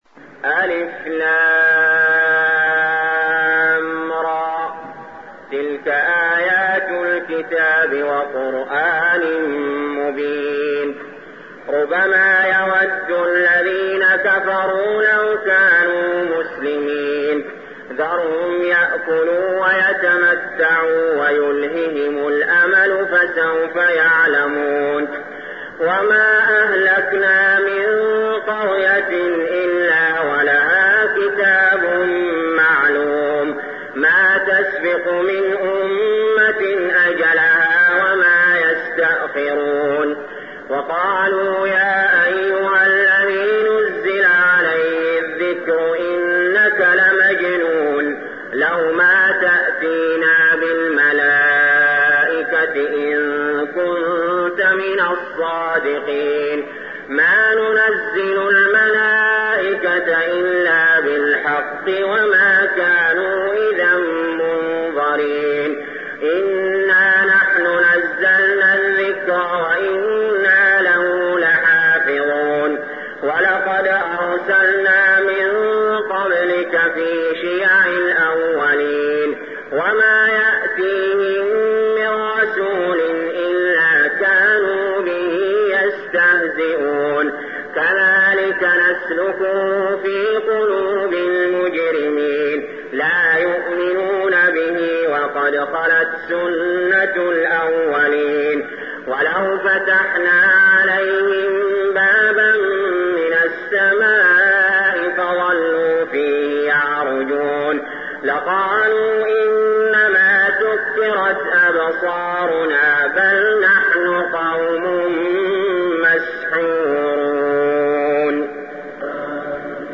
المكان: المسجد الحرام الشيخ: علي جابر رحمه الله علي جابر رحمه الله الحجر